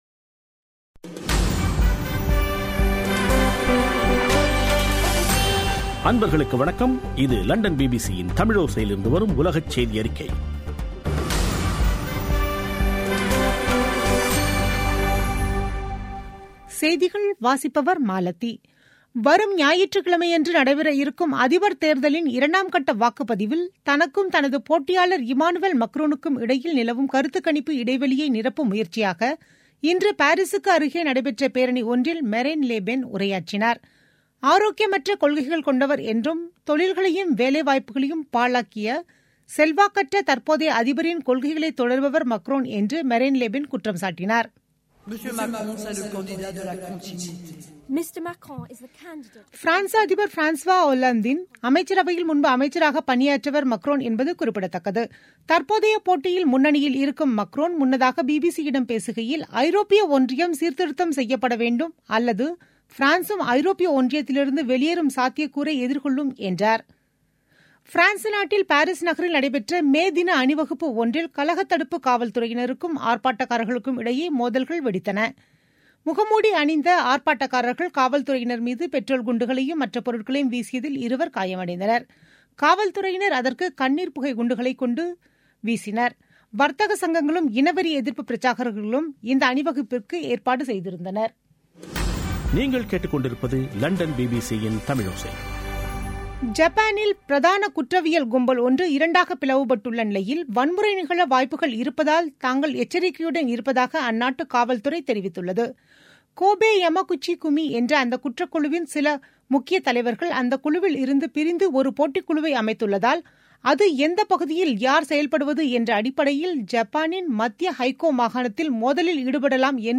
பிபிசி தமிழோசை செய்தியறிக்கை (01/05/2017)